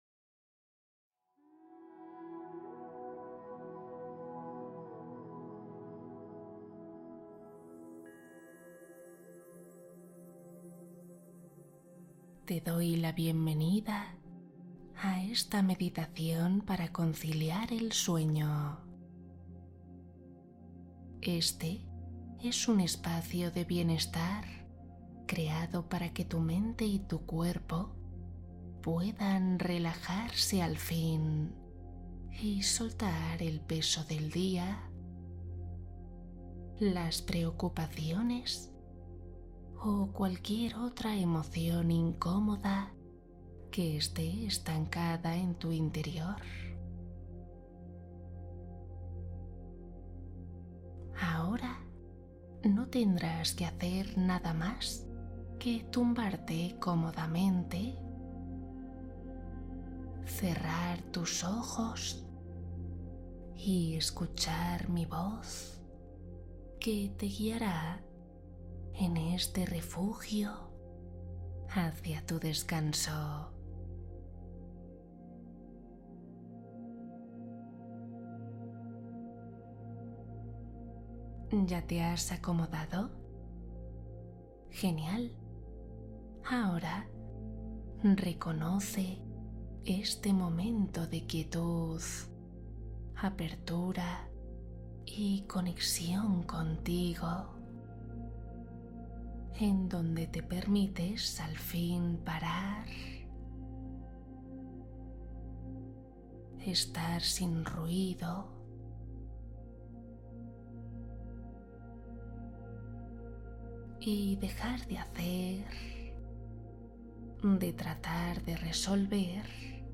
Dormir y sanar: una meditación para restaurar tu energía desde dentro